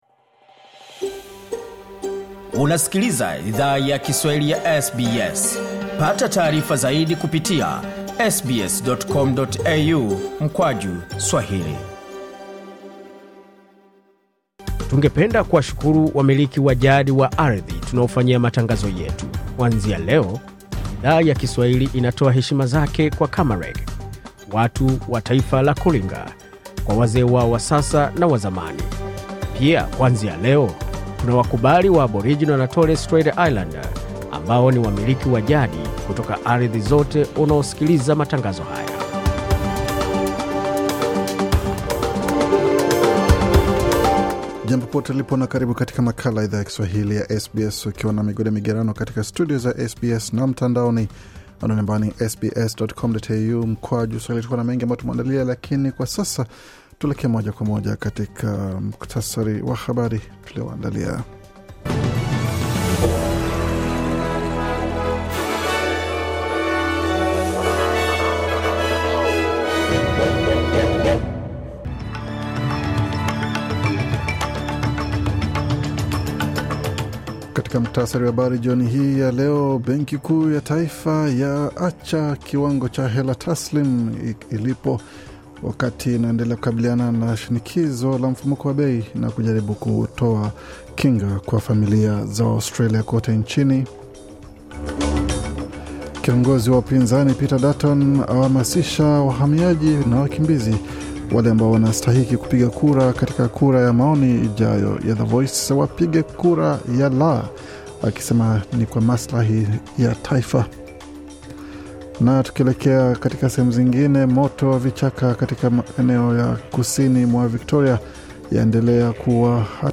Taarifa ya Habari 3 Oktoba 2023